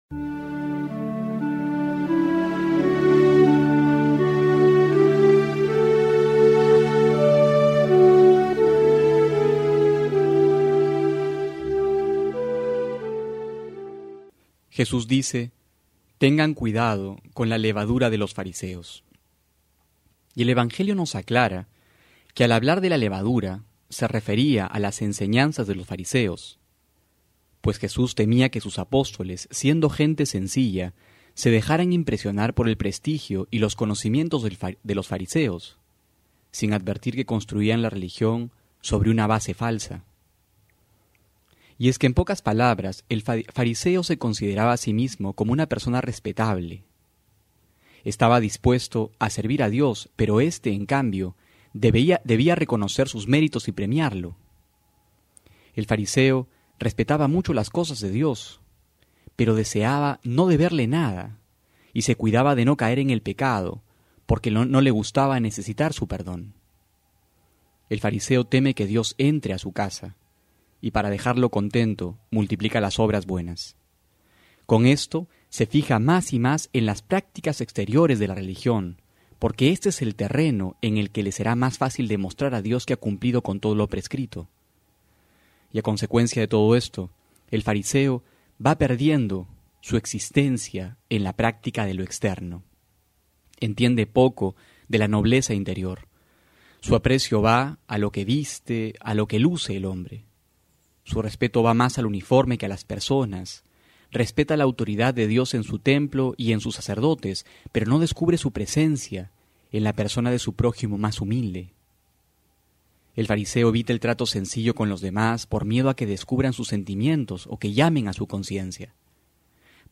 Homilía para hoy: Marcos 8,14-21
febrero14-12homilia.mp3